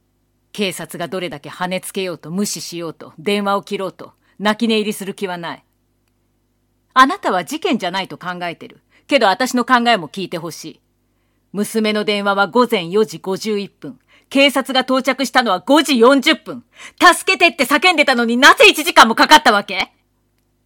ボイスサンプル
怒る女